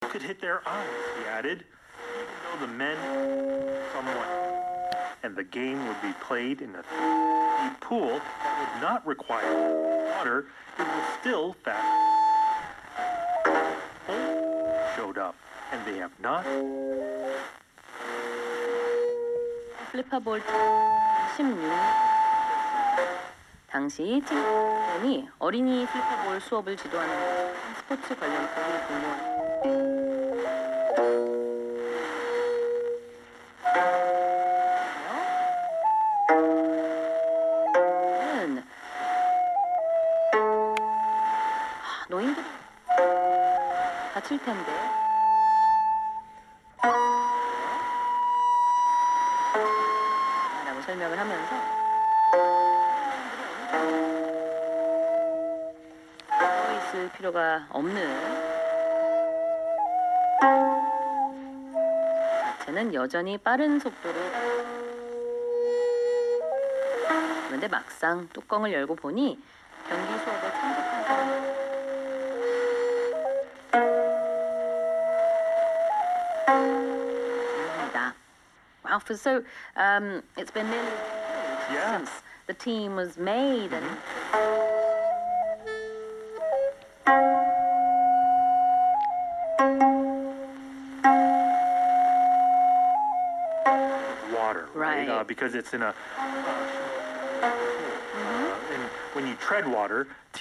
6/28に、韓国方面がオープンしていたとき、国楽放送も107.5MHzと、106.9MHzで聞こえていました。
受信音、EBS FM(推定)と混信しています。厳かな音楽が流れています。
<受信地：岩手県 RX:SIHUADON D-808>